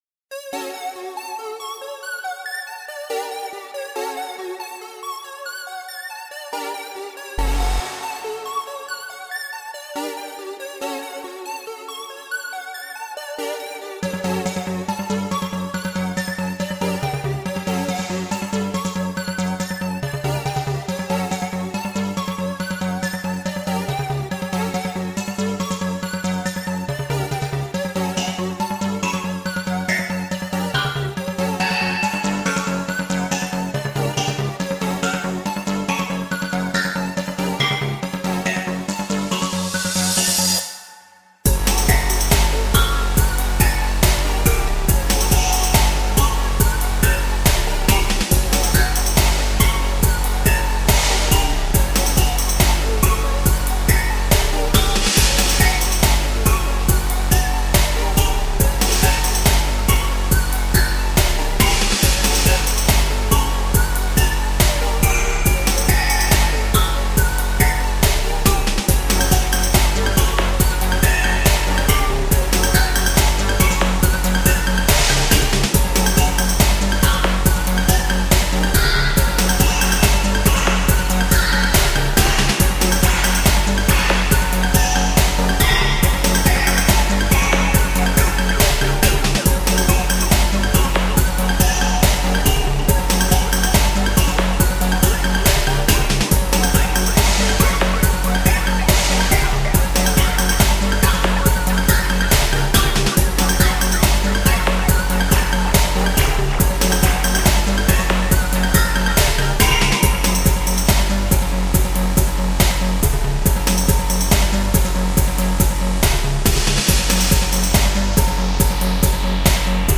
Ruffer than ruff, fresh Mix !